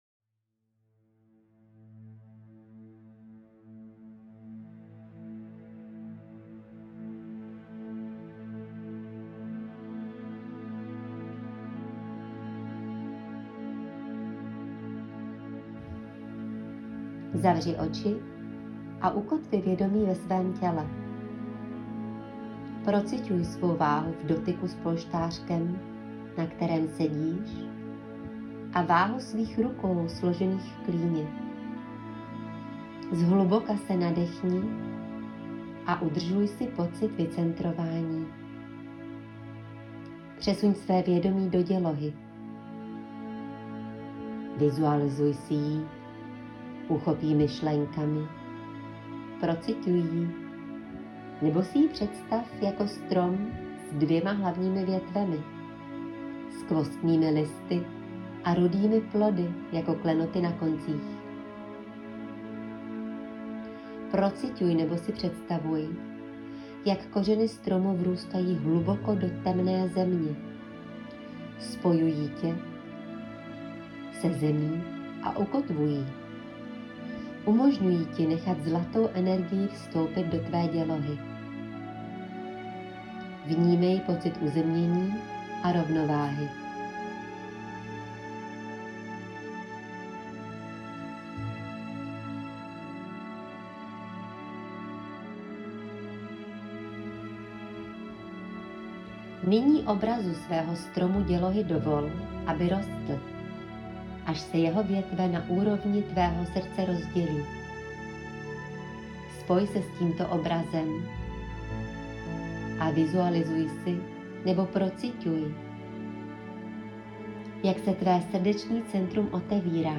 Meditace-stromu-luna.mp3